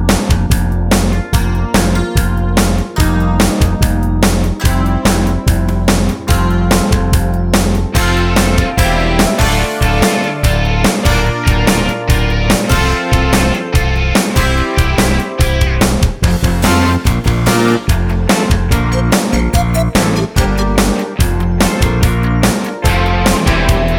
no Backing Vocals Duets 4:58 Buy £1.50